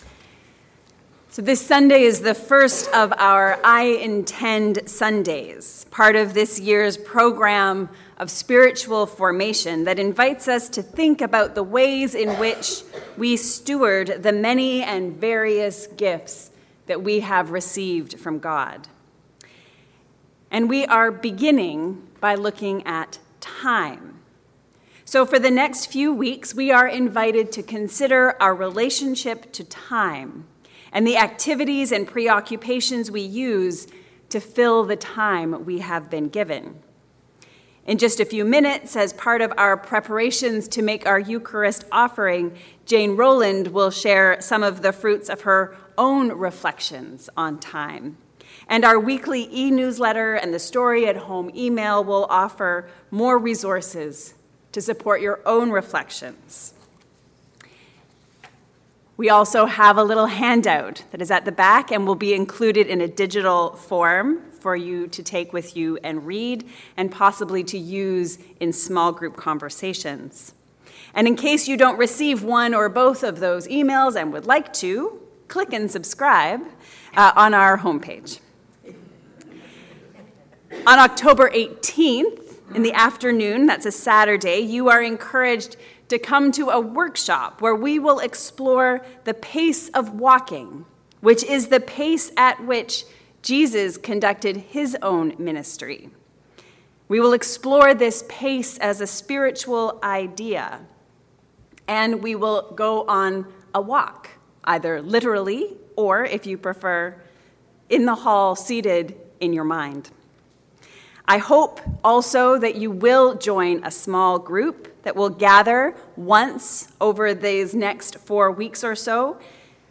This sermon draws on Mark 4:26-29 and Ecclesiastes 3:1-8
Sermon-5-October-2025.mp3